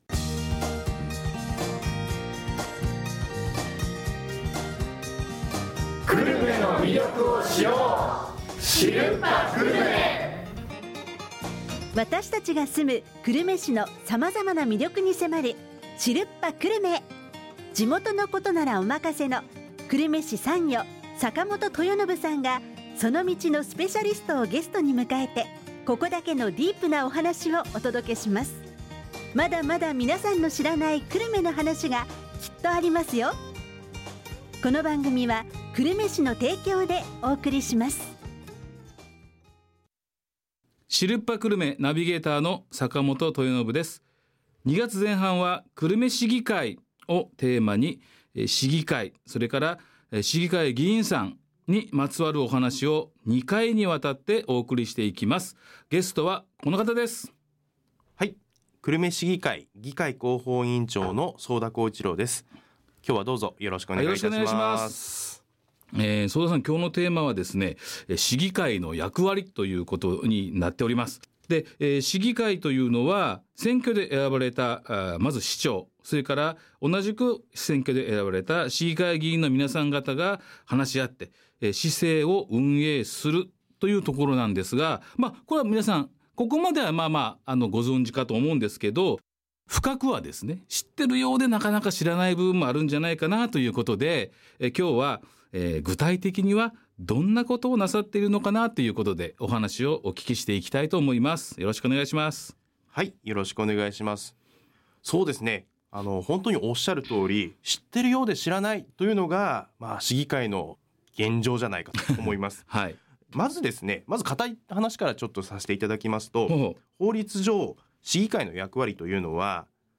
ドリームスFM「知るっぱ久留米」（76.5MHz） 毎週木曜日の、12時30分～12時40分に放送！